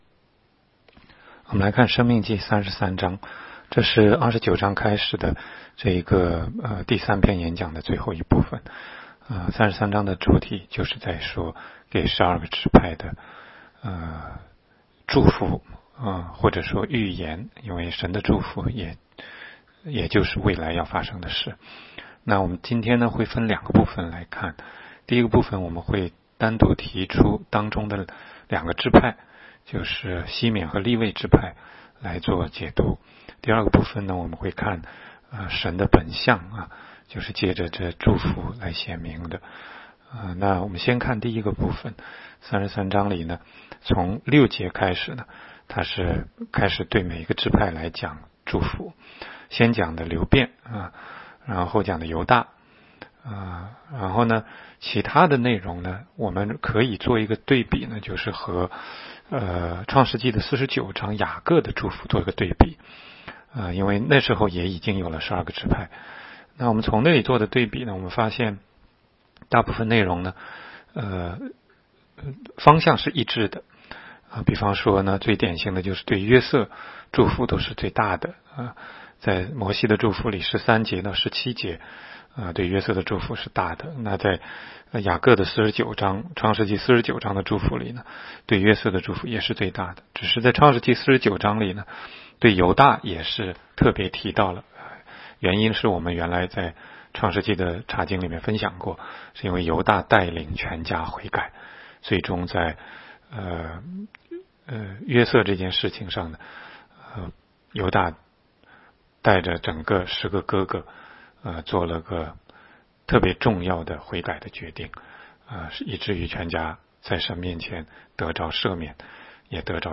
16街讲道录音 - 每日读经-《申命记》33章
每日读经